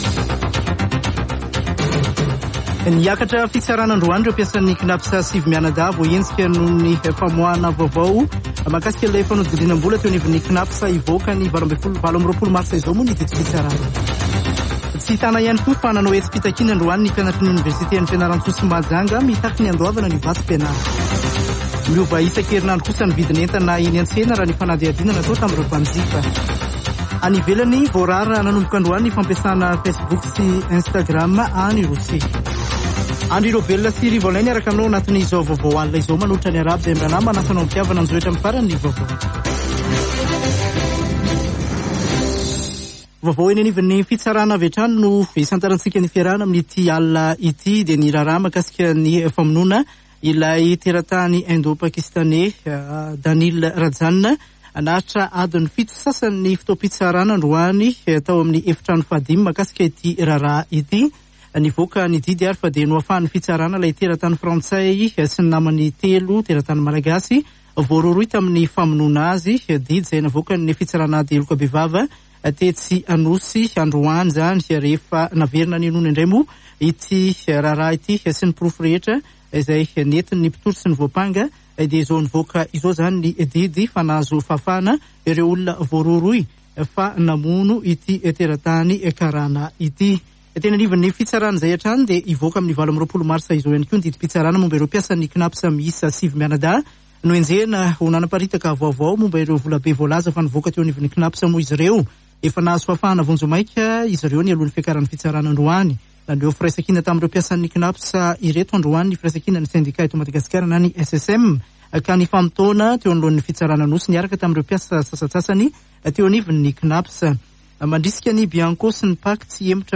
[Vaovao hariva] Alatsinainy 21 marsa 2022